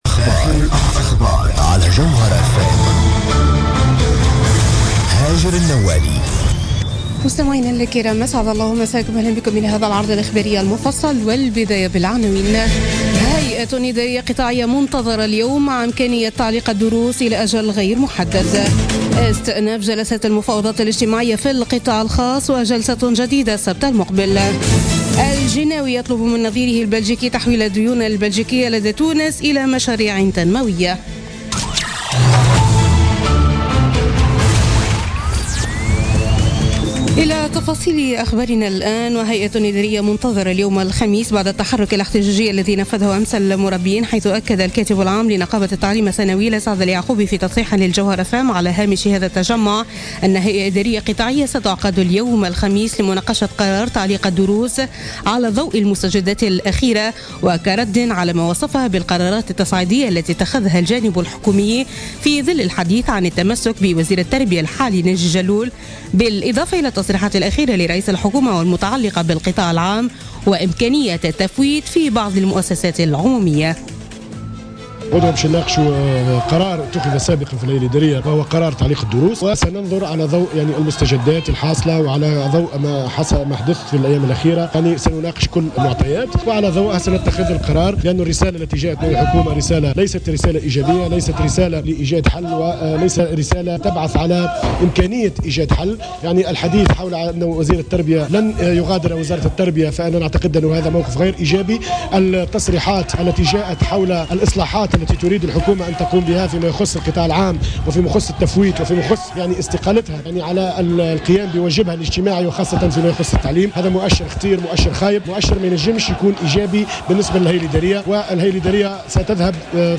نشرة أخبار منتصف الليل ليوم الخميس 2 مارس 2017